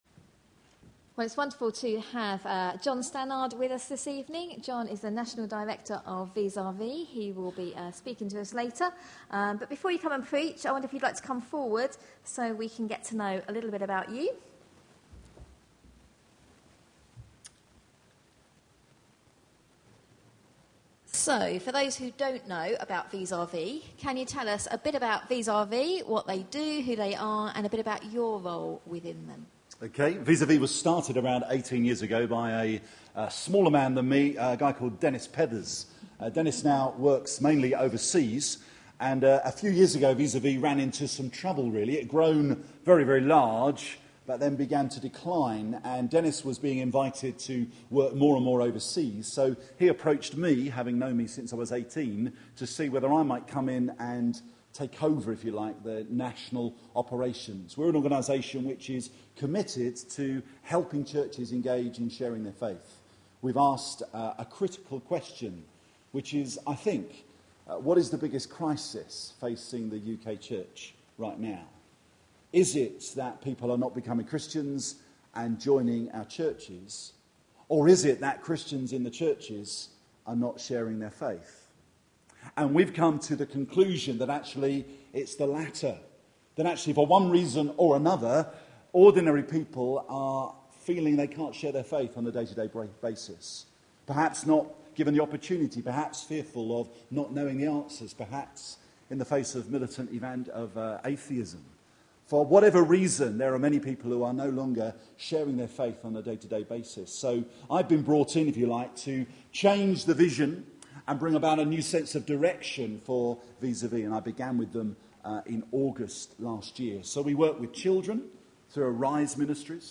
A sermon preached on 22nd April, 2012.